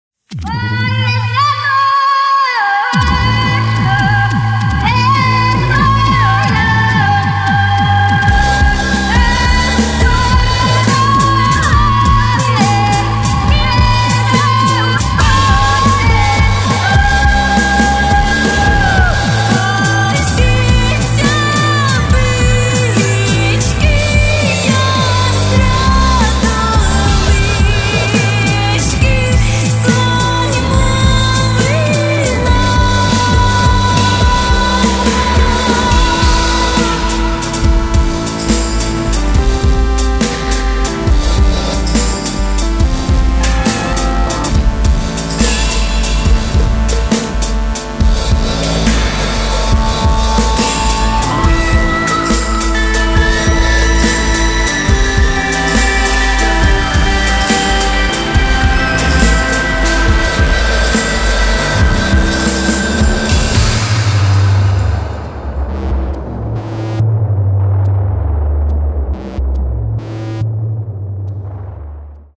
Каталог -> Поп (Легкая) -> Этно-поп